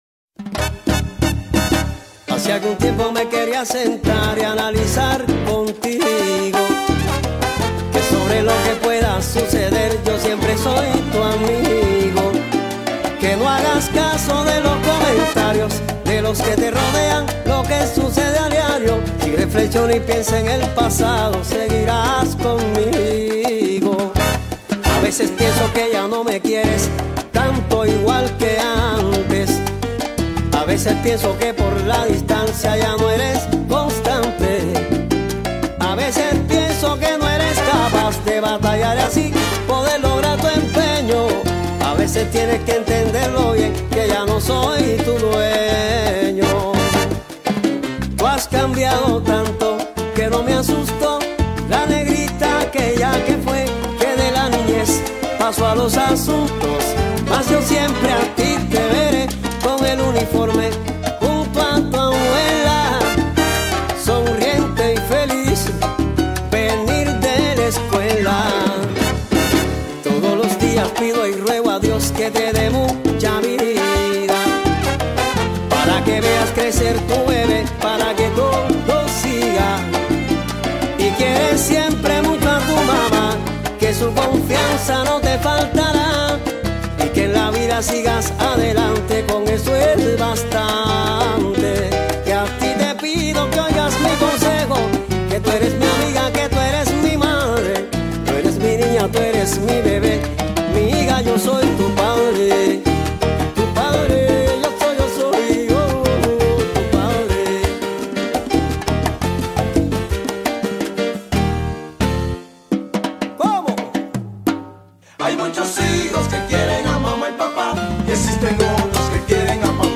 ¡SALSA INTERNACIONAL!